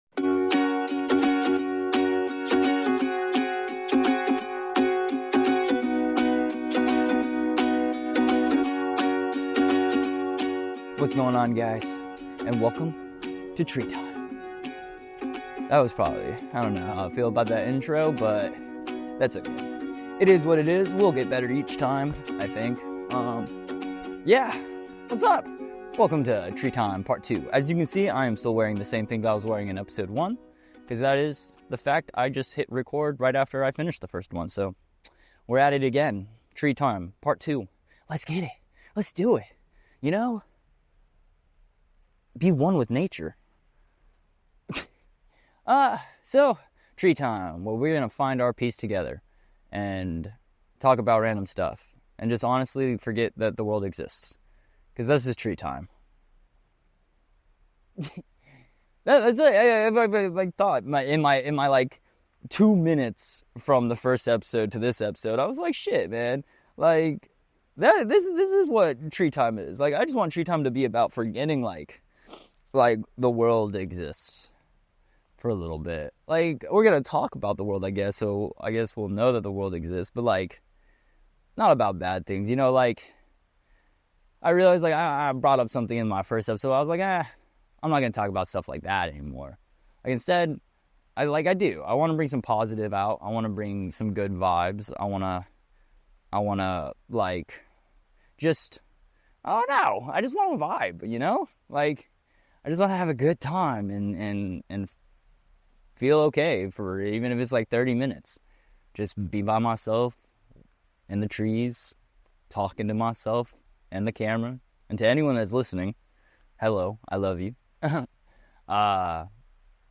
We are all out here trying to find our peace and what better way to find it than to listen to a guy in a wooded area, talking to himself!!